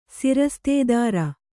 ♪ sirastēdāra